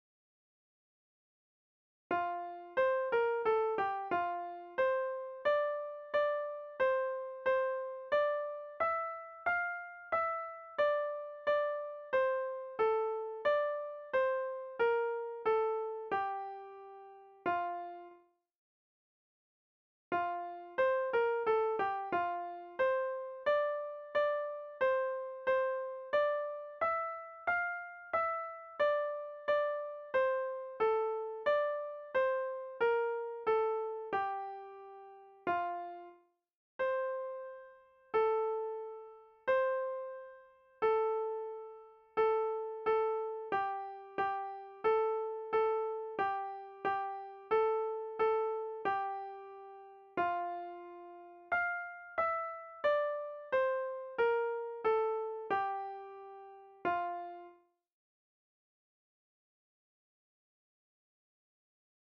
sopran-solo-Bach-erschallet-ihr-lieder-schlusschoral.mp3